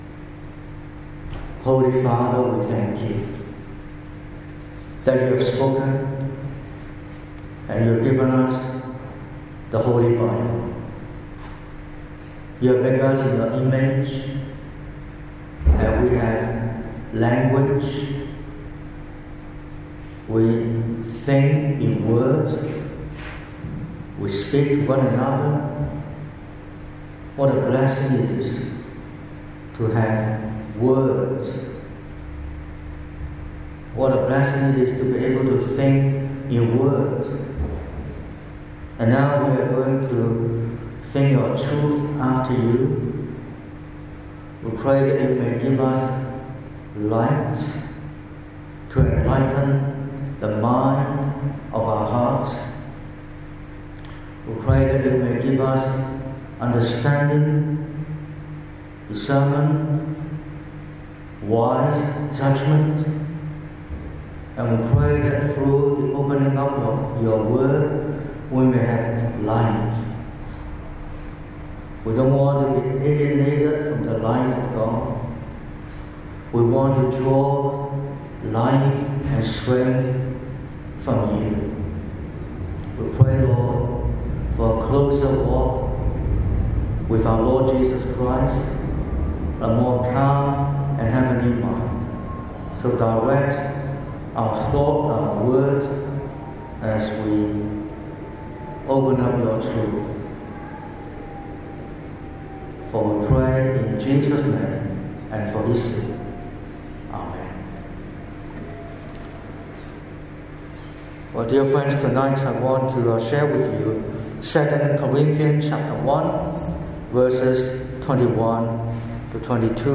Weekly sermons from Maroubra Presbyterian Church